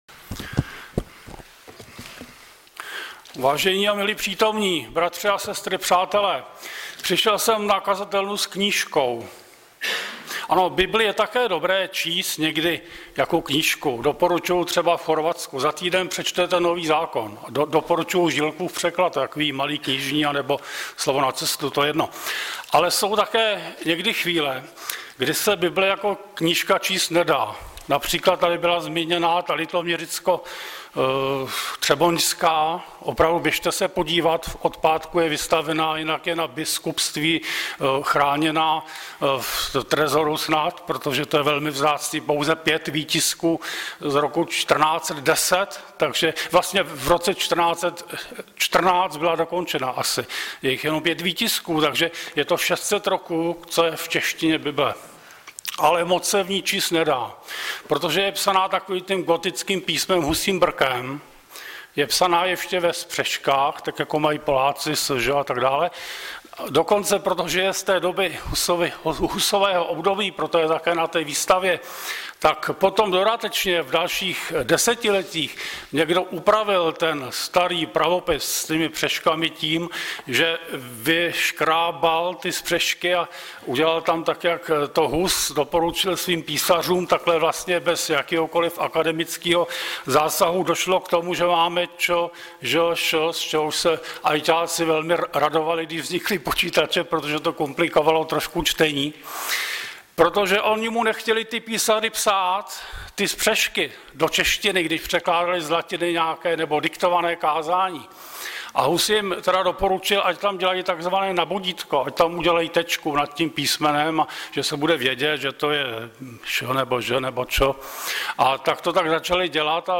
Webové stránky Sboru Bratrské jednoty v Litoměřicích.
Kázání